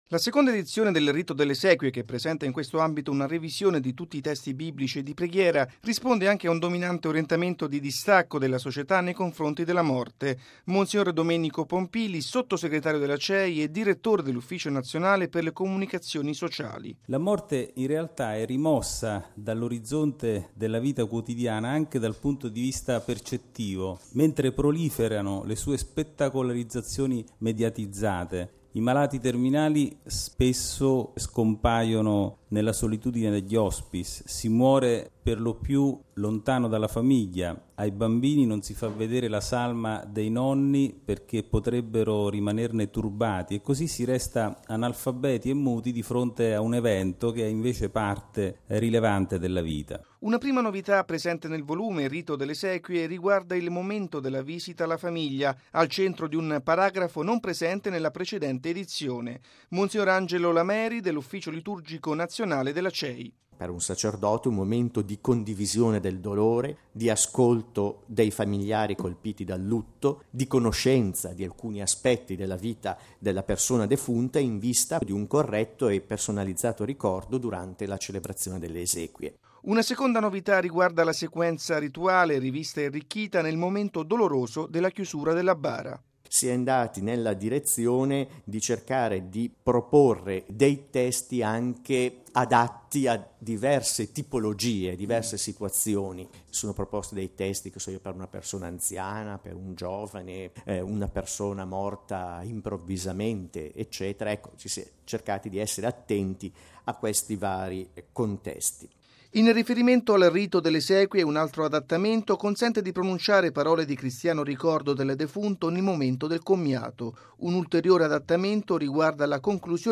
Mons. Alceste Catella, vescovo di Casale Monferrato, presidente della Commissione episcopale per la liturgia: